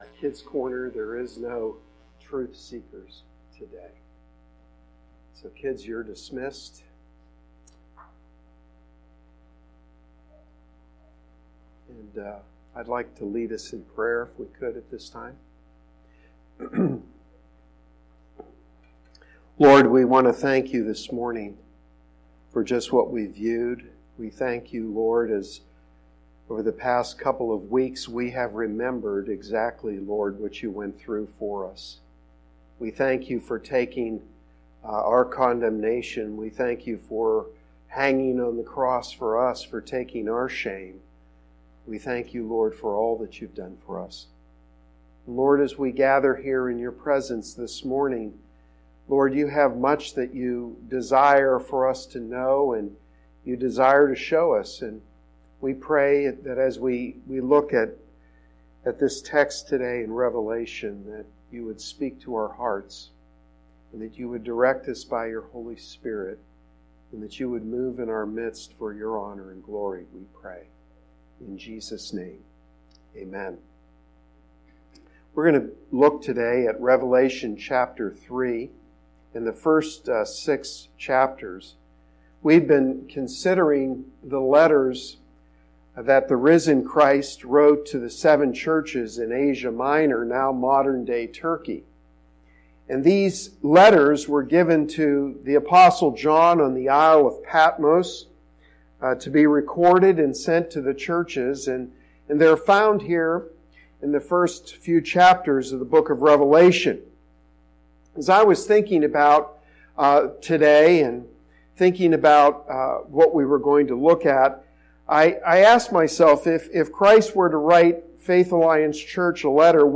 Sermon-4-28-19.mp3